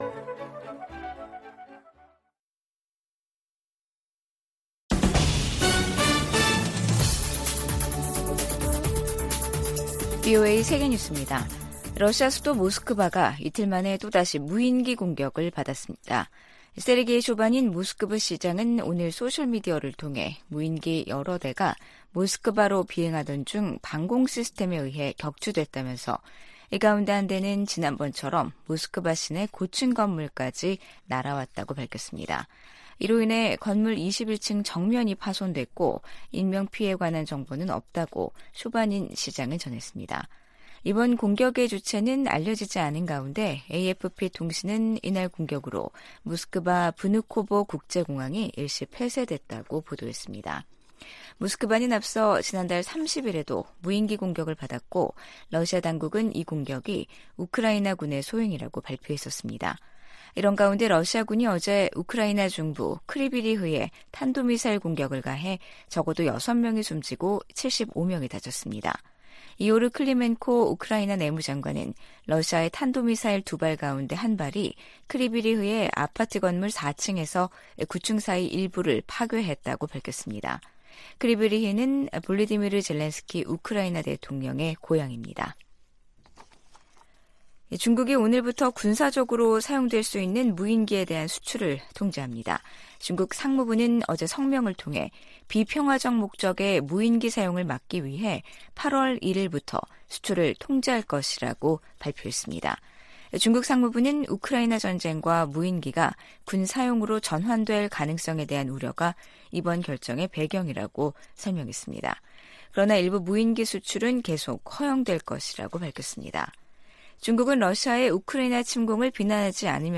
VOA 한국어 간판 뉴스 프로그램 '뉴스 투데이', 2023년 8월 1일 2부 방송입니다. 린다 토머스-그린필드 유엔 주재 미국대사가 북한의 식량 불안정 문제는 정권이 자초한 것이라고 지적했습니다. 제11차 핵확산금지조약(NPT) 평가회의 첫 준비 회의에서 주요 당사국들은 북한이 비확산 체제에 도전하고 있다고 비판했습니다. 북한과 러시아 간 무기 거래 가능성이 제기되는 데 대해 미국 국무부는 추가 제재에 주저하지 않을 것이라고 강조했습니다.